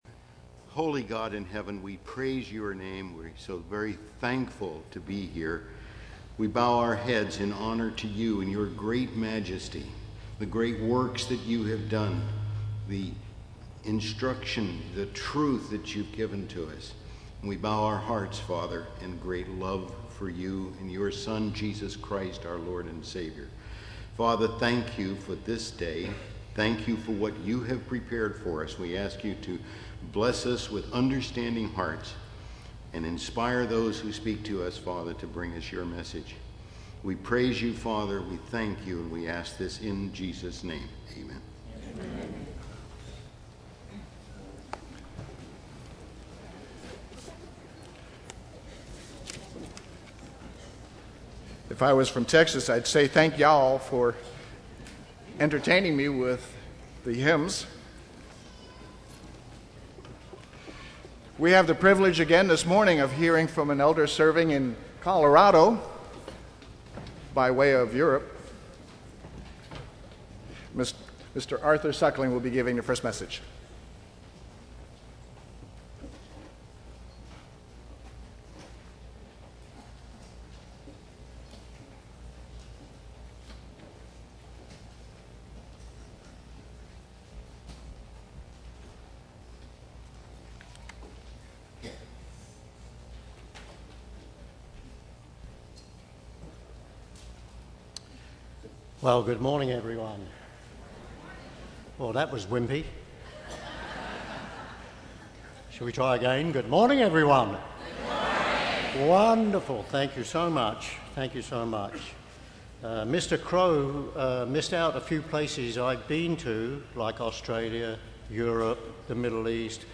This sermon was given at the Oceanside, California 2014 Feast site.